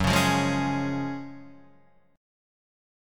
F# Major 7th Suspended 2nd